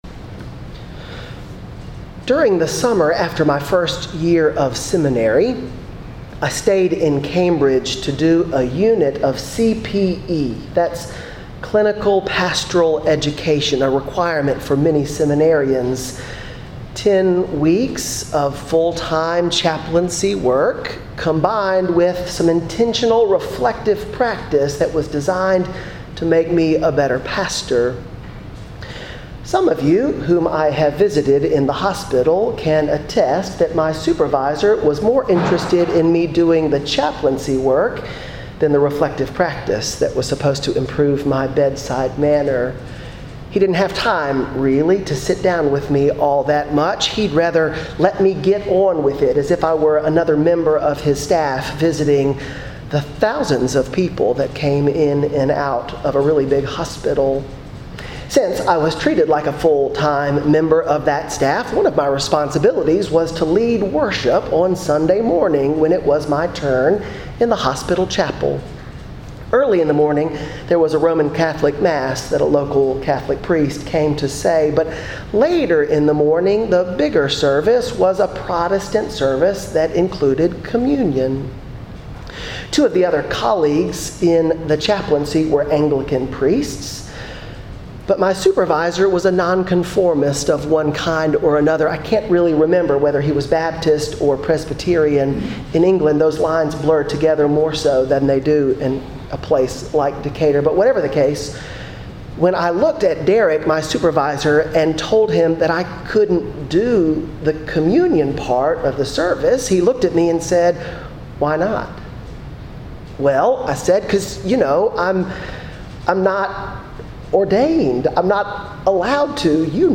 June 19, 2018 - Tuesday in Proper 6 2 Kings 2:1, 6-14 ; Matthew 6:1-6, 16-18 Audio of this sermon can be heard here .